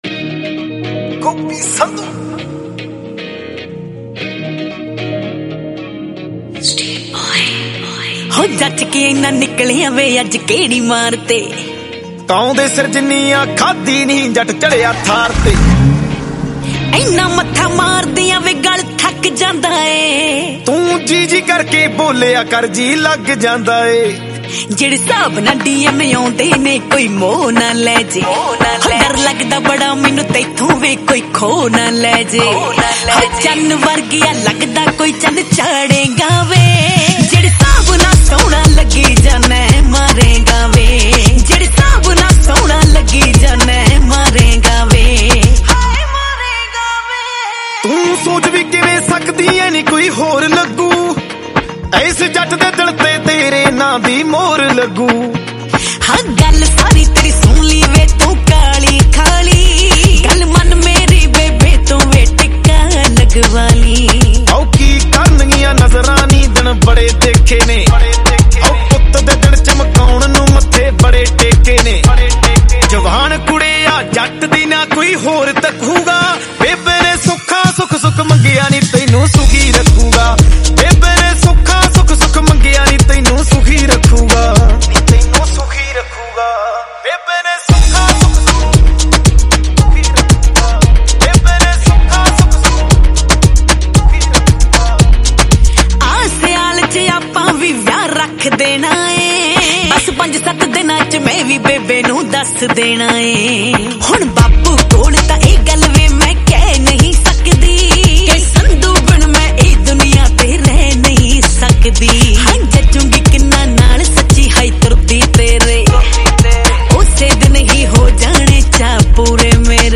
Punjabi Single Track